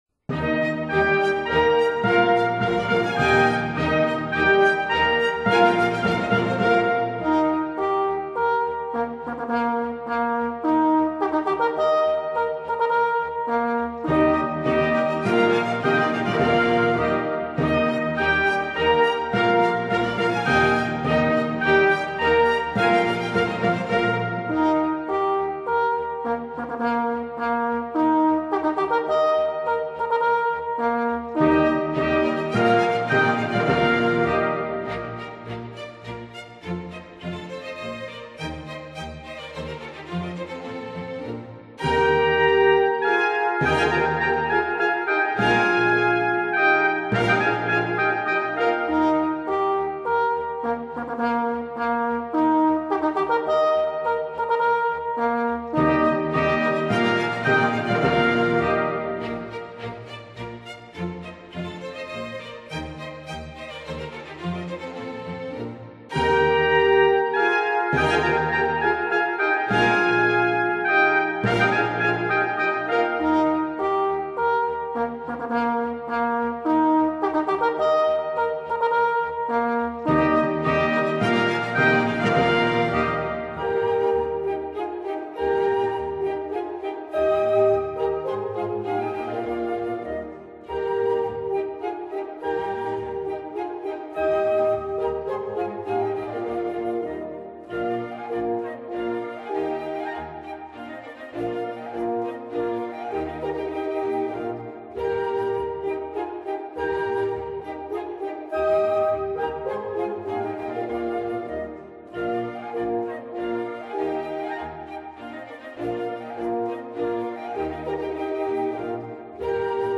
Alla polacca